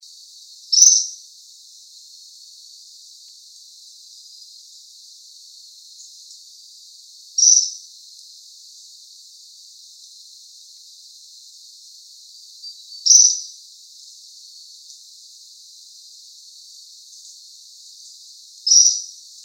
Ticotico Ocráceo (Anabacerthia lichtensteini)
Nombre en inglés: Ochre-breasted Foliage-gleaner
Fase de la vida: Adulto
Localidad o área protegida: Reserva Privada y Ecolodge Surucuá
Condición: Silvestre
Certeza: Fotografiada, Vocalización Grabada